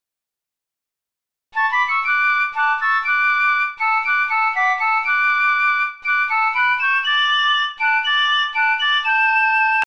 Basler Märsch
(numme d Aafäng)